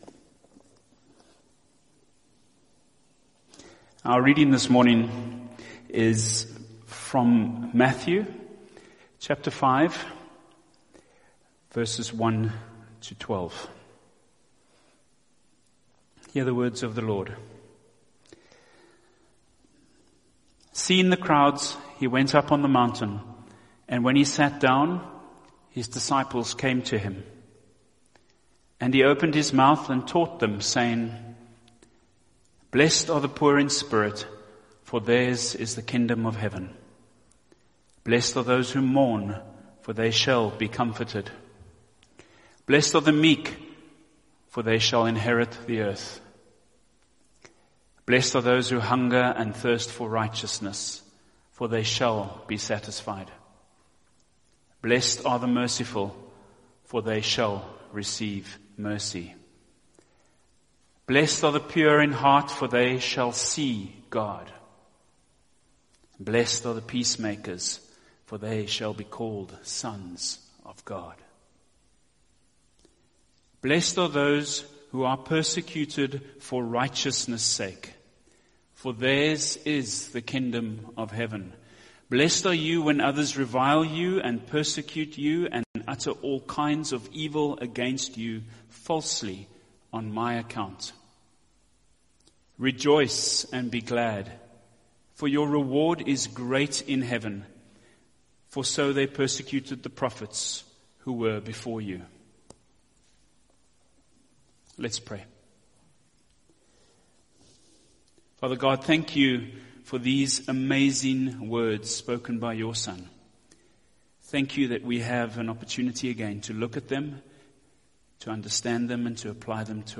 Sermon Series: Beatitudes